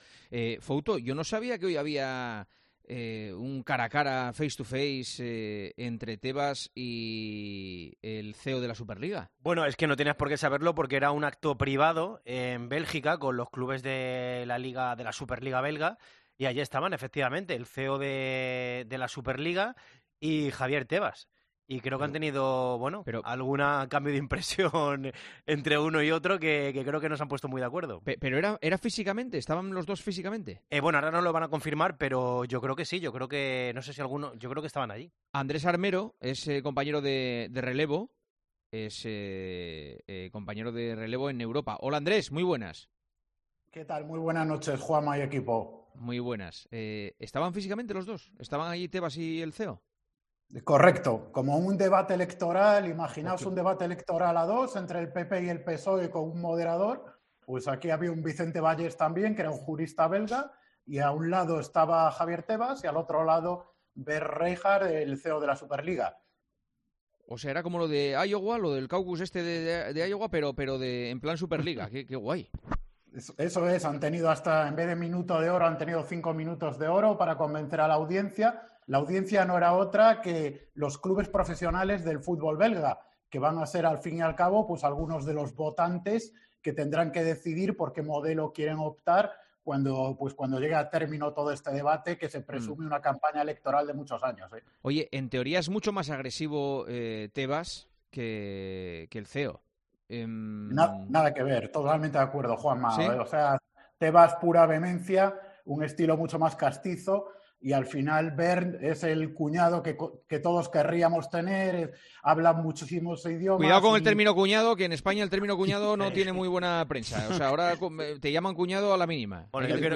El presidente de LaLiga y Reichart han tenido un debate en Bélgica frente a los representantes de los equipos de la competición doméstica del país para explicar ambas posturas.